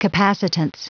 Prononciation du mot capacitance en anglais (fichier audio)
Prononciation du mot : capacitance